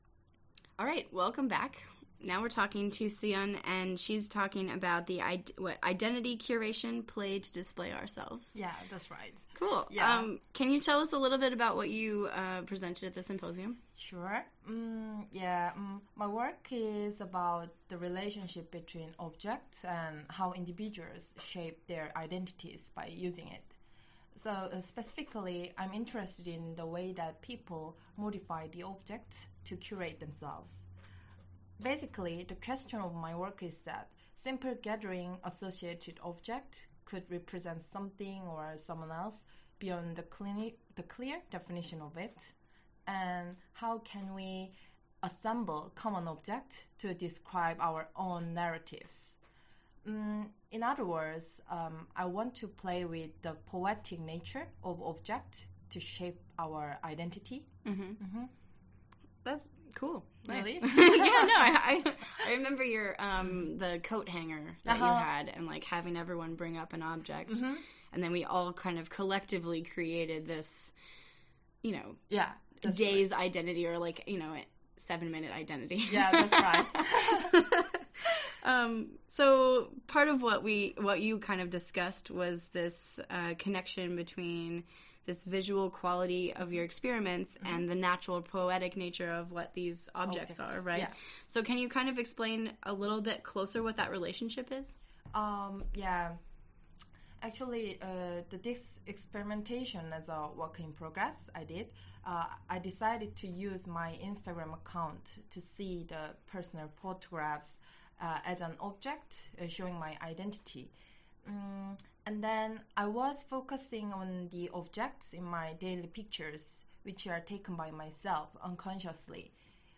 (02. 2018) Graphically Speaking published these ideas via audio interviews of each class member, running as a live broadcast from Central Saint Martins (accessible in-person and online) for the duration of the show.
The audio was broadcast live from the GCD studios in Central Saint Martins and archived online.